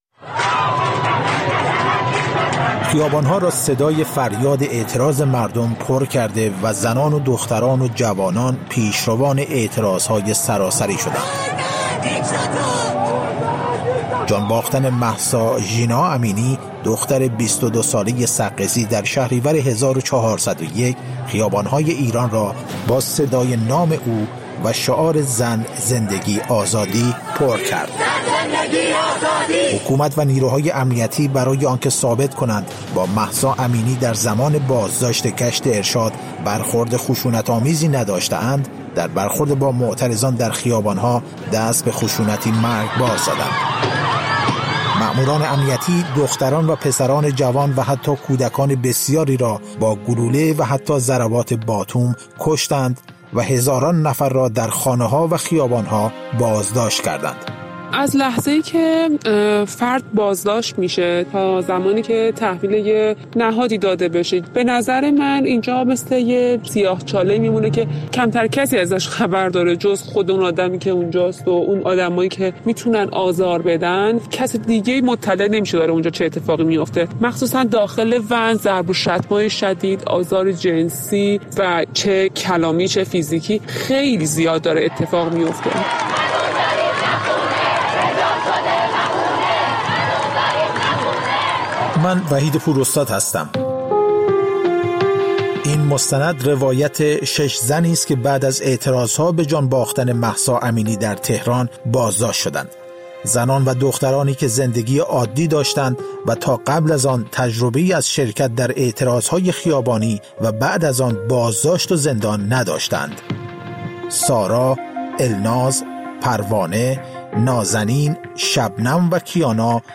مستند رادیویی: سیاه‌چاله؛ روایت زنان از و‌ن‌های پلیس